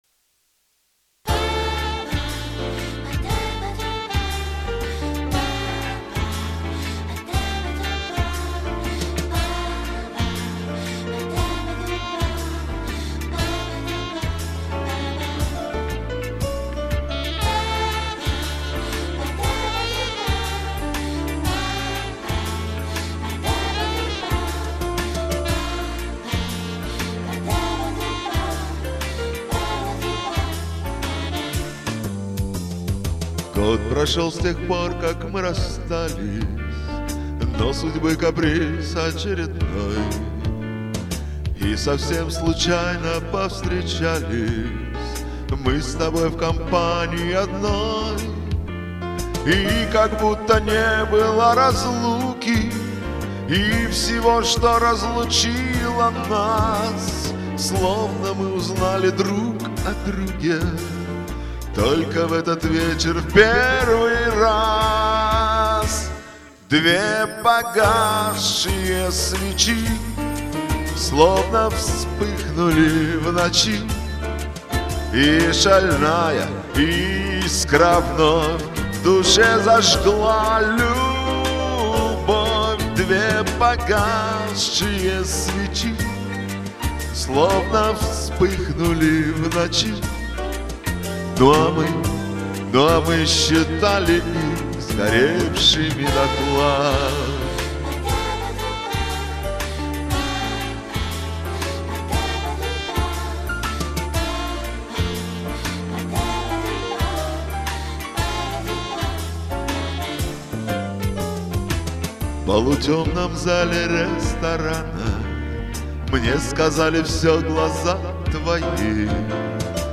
Некий диссонанс в моем восприятии возник.
Настоящий шансон!!Молодцы!!!
Но второе исполнение мне показалось более выразительным!!2-3